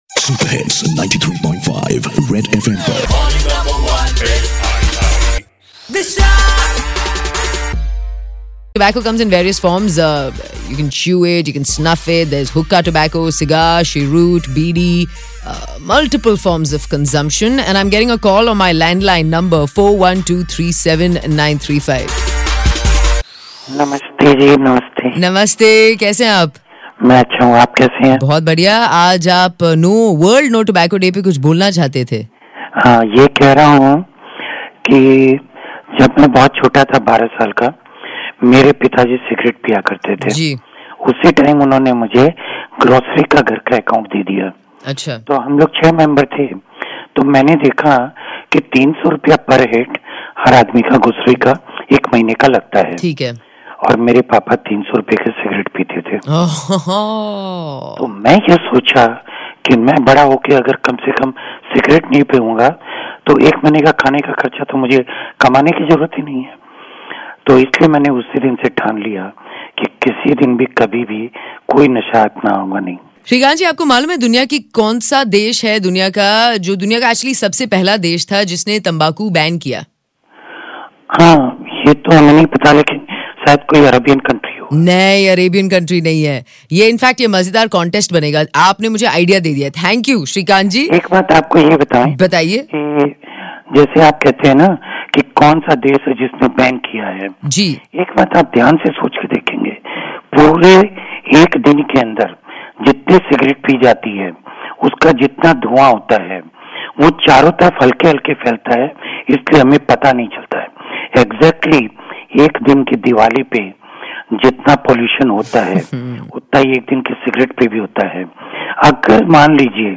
a listener talks on World No Tobacco Day